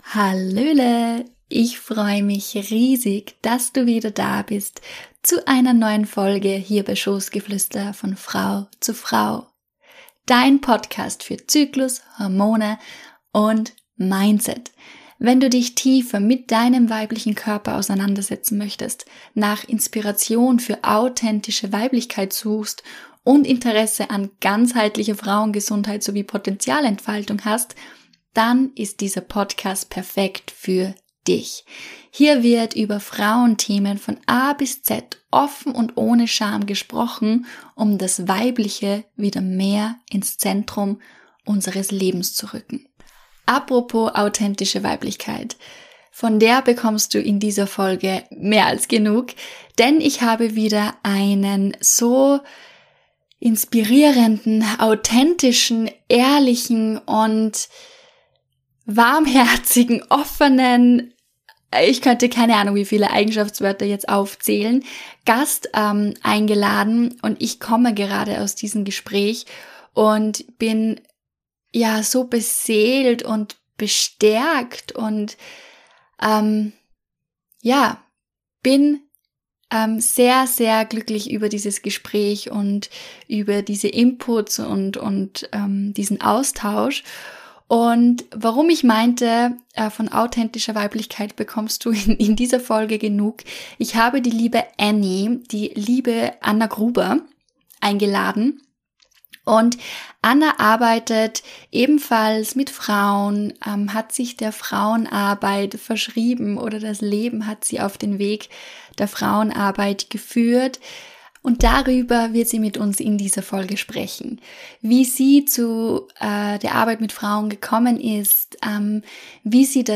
✨ Eine inspirierende Unterhaltung, die dich auf eine Reise durch die Welt der Weiblichkeit und den weiblichen Rhythmen mitnimmt.